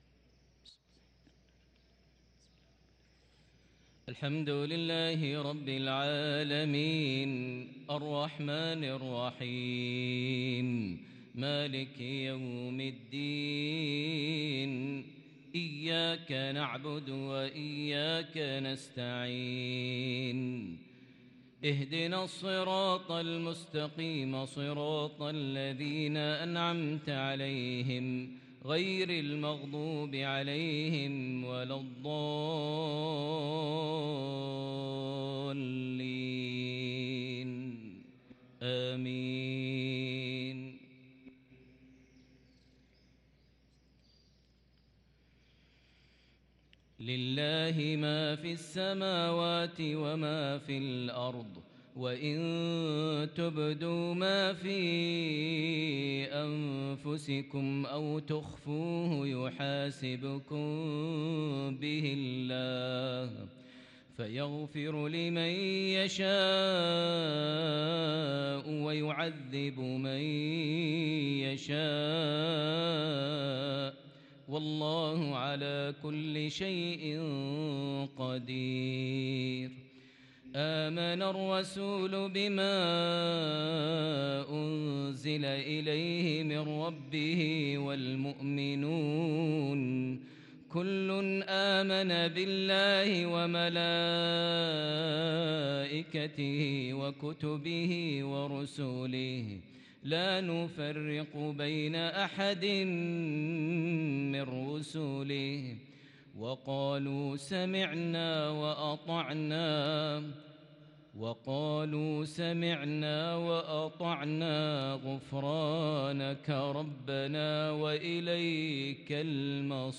صلاة المغرب للقارئ ماهر المعيقلي 8 صفر 1444 هـ
تِلَاوَات الْحَرَمَيْن .